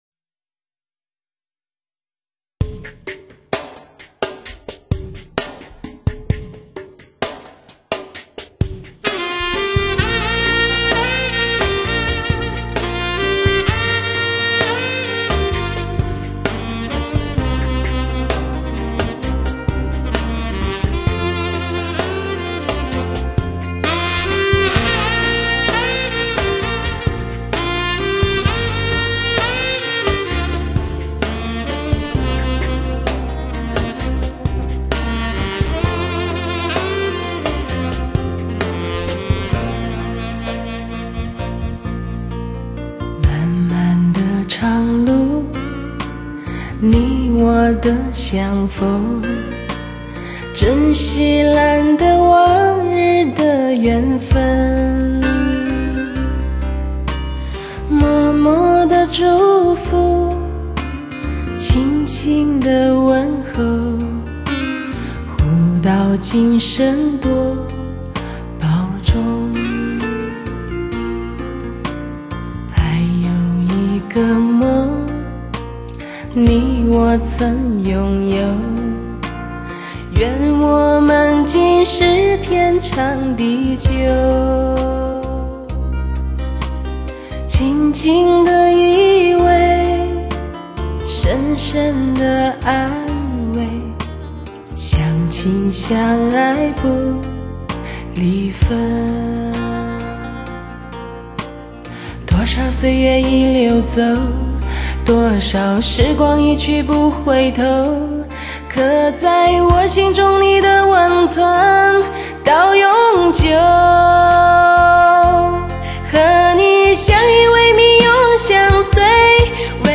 首席浓情浪漫歌伶，新时代罕见的清丽嗓音。